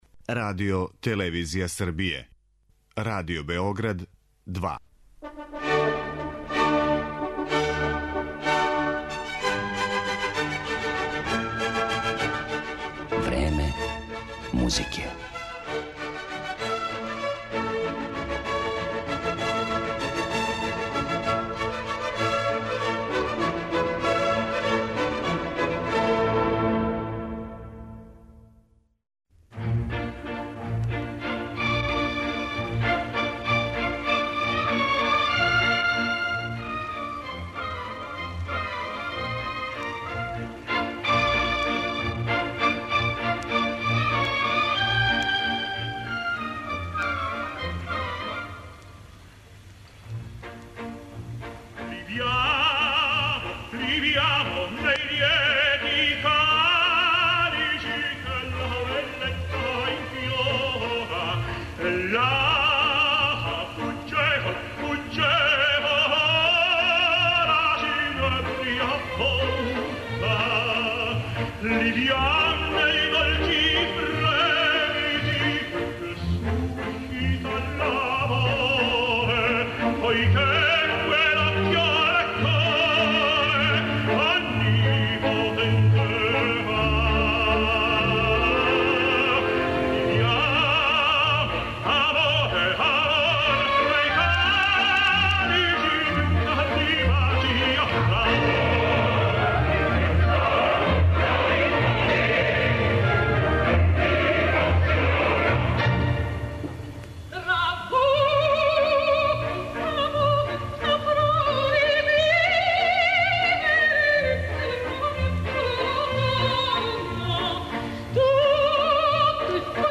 Између осталог, моћи ћете да чујете и легендарни архивски снимак продукције опере "Травијата", Ђузепа Вердија, коју је режирао Лукино Висконти и у којој су солисти били Марија Калас и Ђузепе ди Стефано.